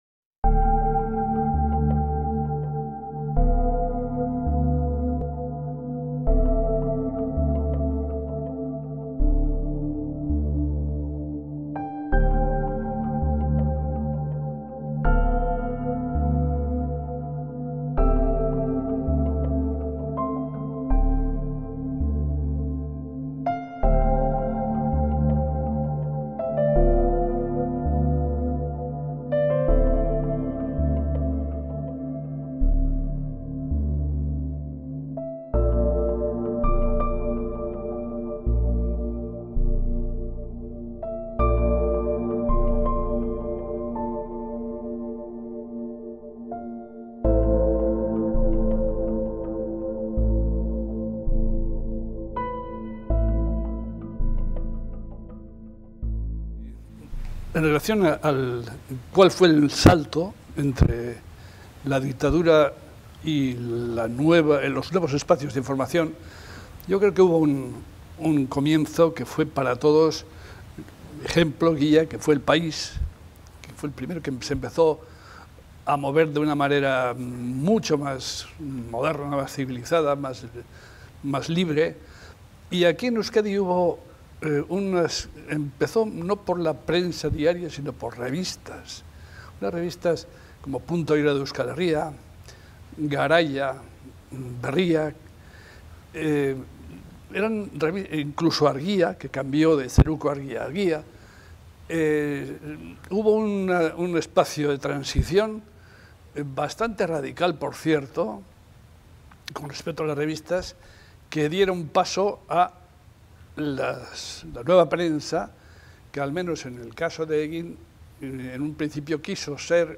dos veteranos periodistas